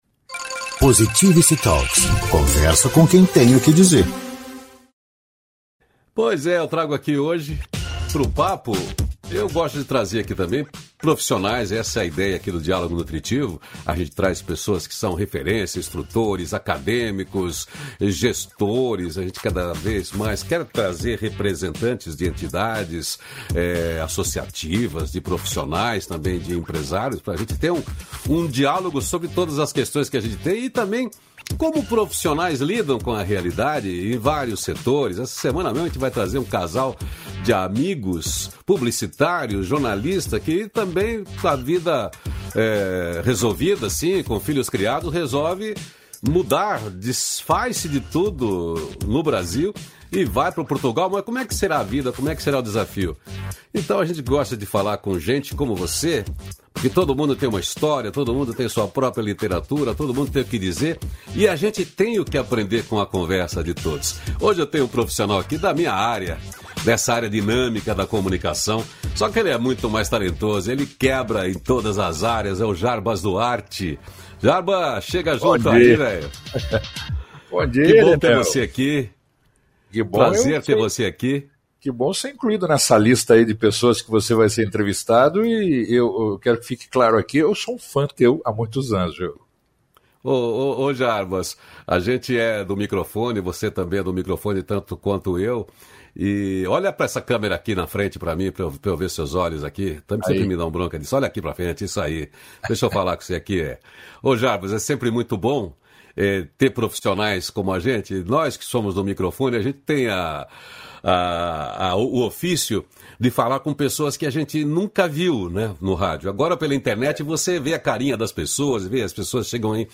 305-feliz-dia-novo-entrevista.mp3